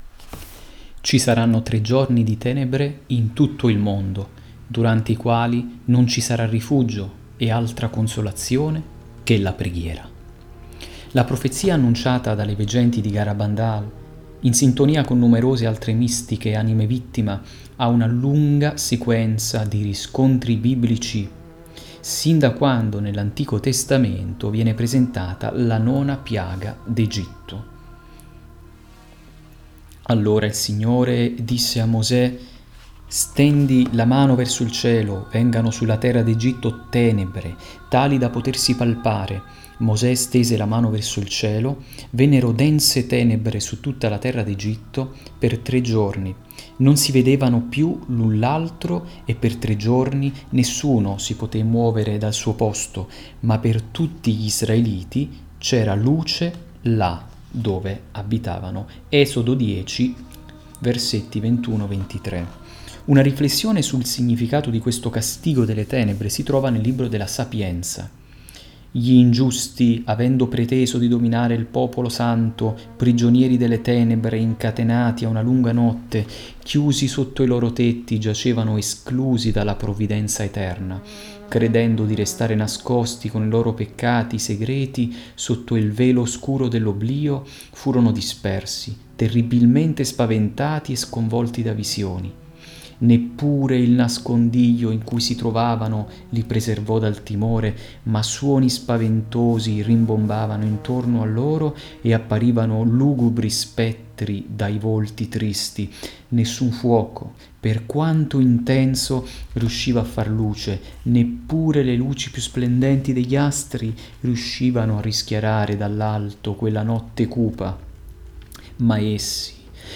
In questo podcast che ho caricato do lettura dell’avvincente capitolo del libro le “Veggenti”.